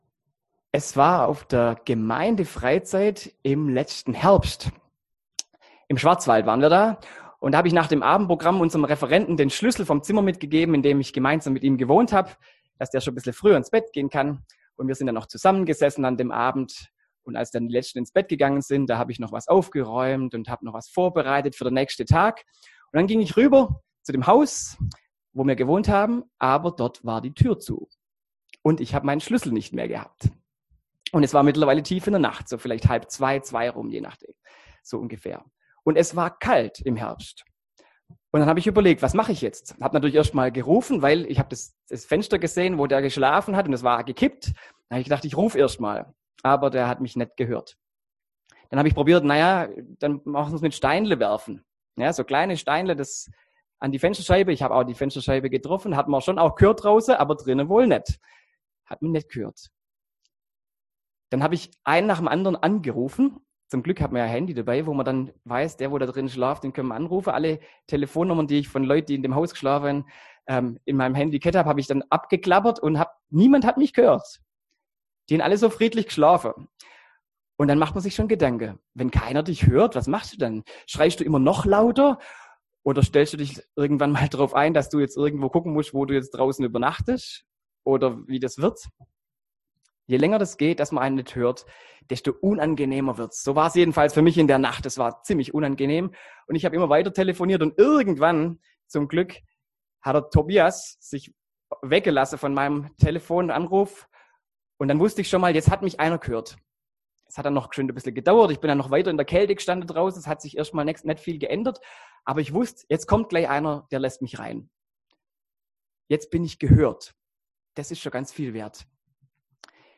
Predigt zur Jahreslosung für 2021 aus Lk 6,36: Seid barmherzig, wie auch euer Vater barmherzig ist.